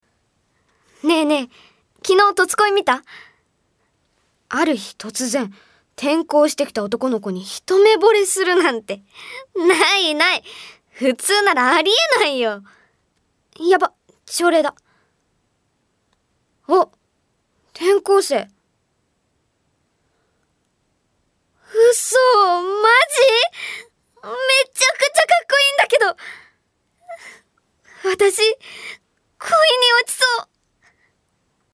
Voice Sample